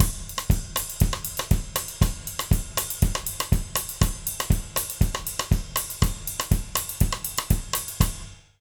120ZOUK 08-L.wav